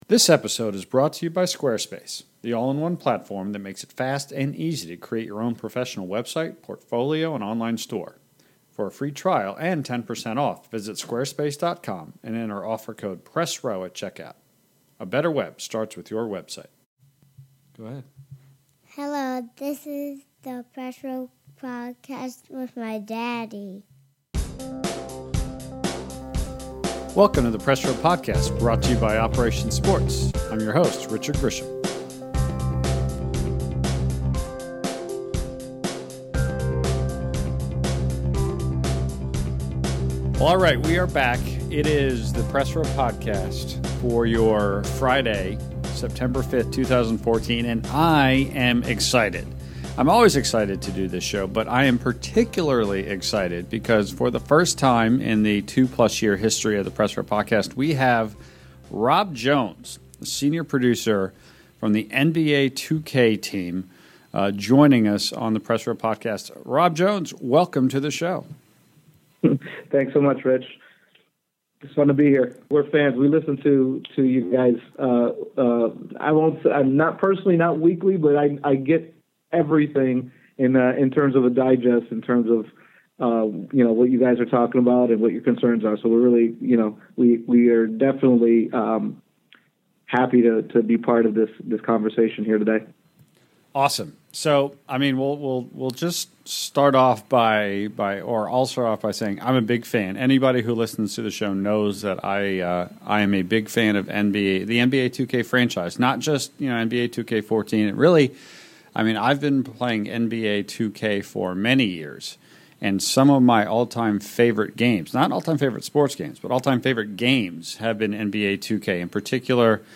It’s a fascinating look into the mindset of the NBA 2K team, and can only be found here at Operation Sports on the Press Row Podcast.